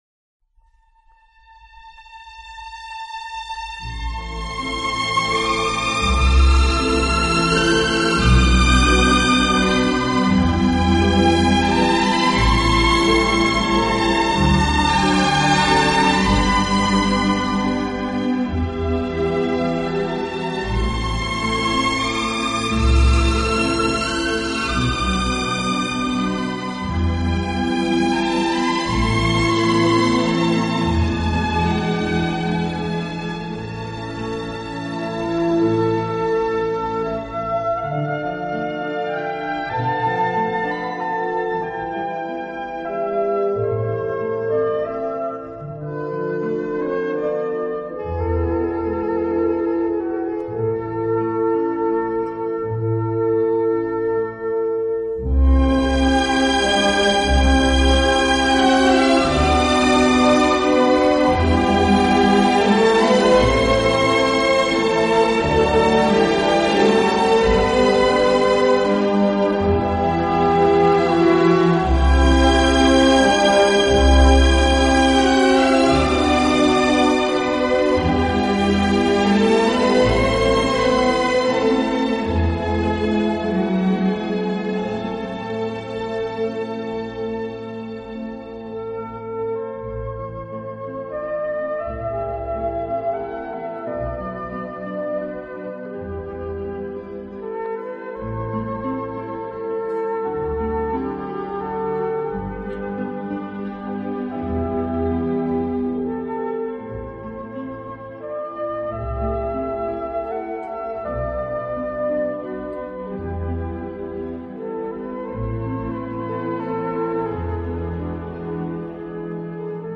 这个乐团的演奏风格流畅舒展，
旋律优美、动听，音响华丽丰满。